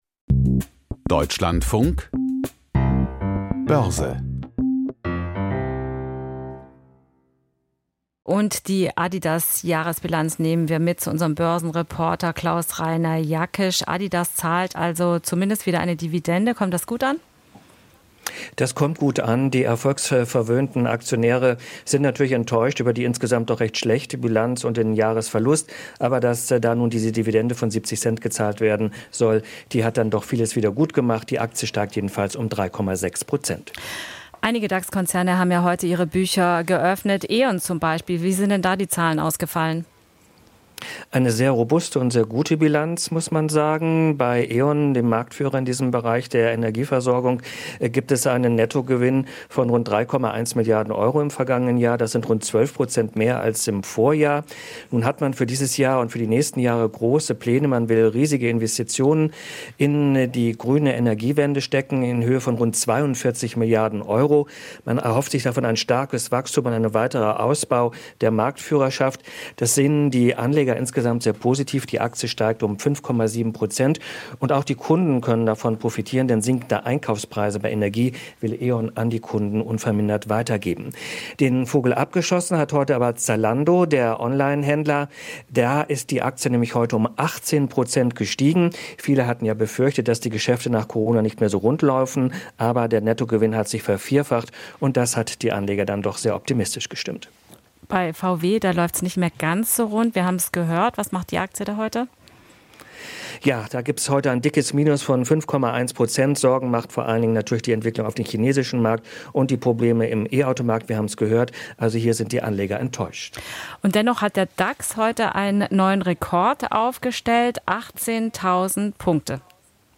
Börsenbericht aus Frankfurt